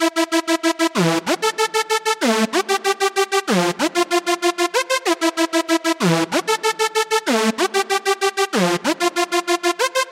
95Bpm的雷盖顿西塔拉循环曲
描述：Reggaeton old school ... recuerdos :)
标签： 95 bpm Reggaeton Loops Sitar Loops 1.70 MB wav Key : G FL Studio
声道立体声